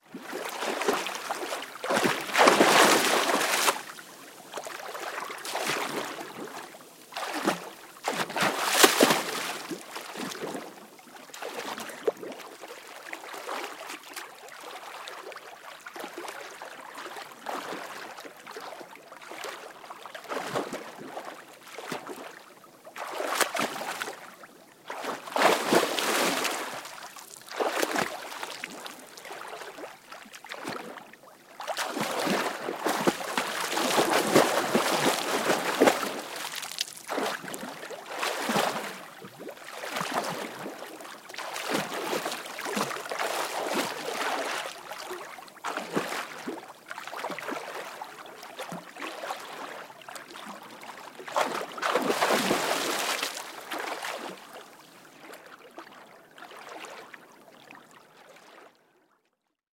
Звуки плавания
Шум плавания в воде